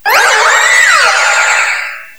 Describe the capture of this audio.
The cries from Chespin to Calyrex are now inserted as compressed cries